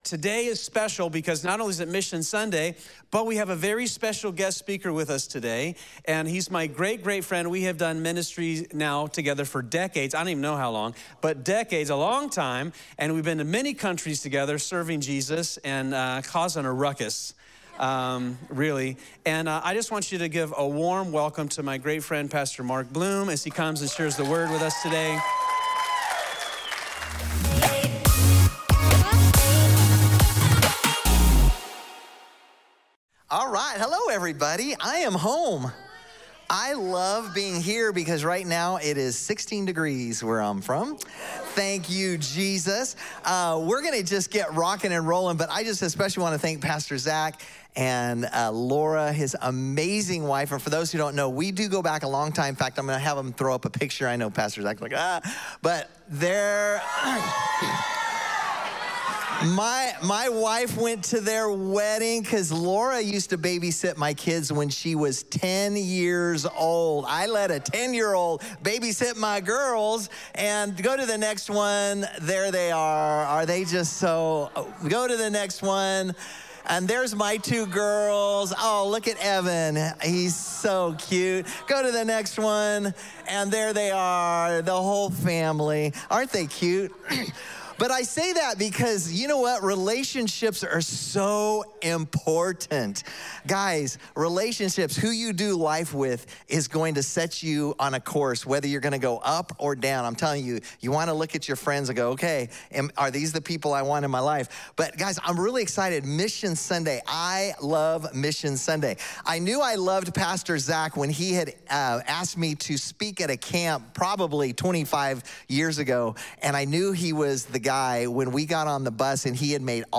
Evangelism Gospel Missions Sunday Morning Before Jesus left earth, he told his disciples to let the whole world know about the gospel.